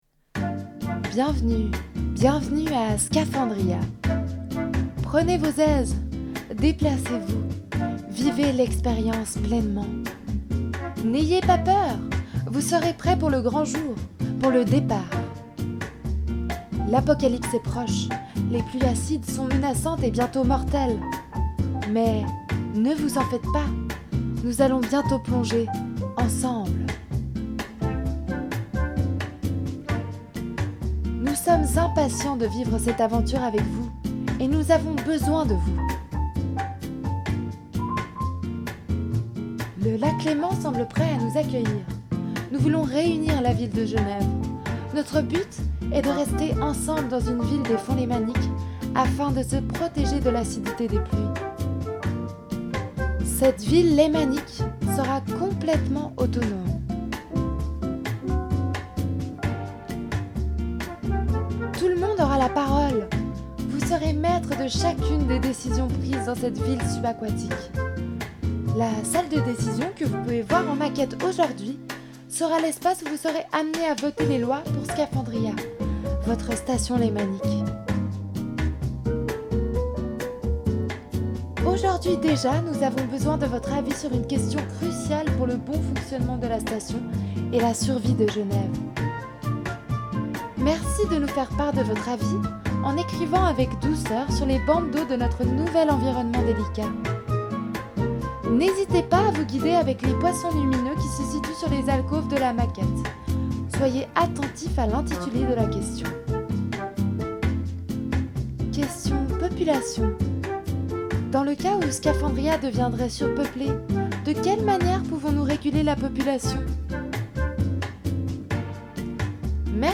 Les visiteurs sont aussi invités à répondre à certaines questions posé par une voix, drôles ou dramatiques, concernant l’avenir de la colonie subaquatique.